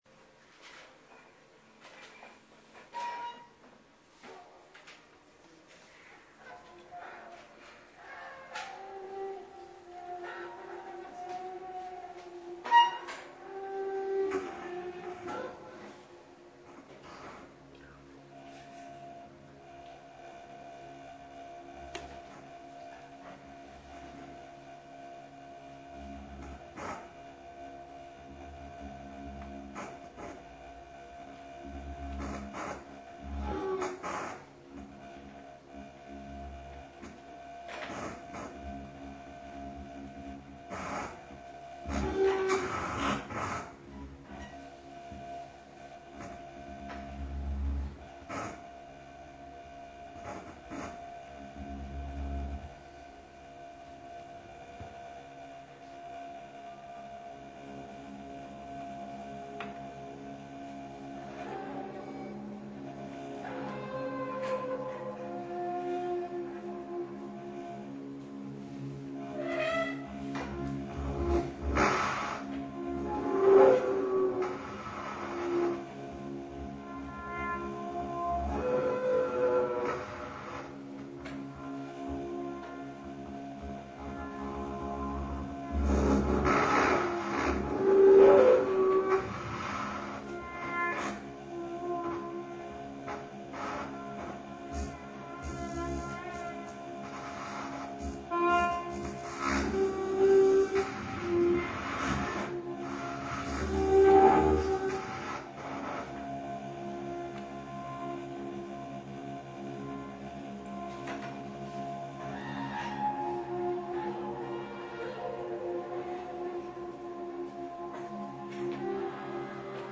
elektronika
hurdy gurdy
Elektroakustična improvizacija in video v živo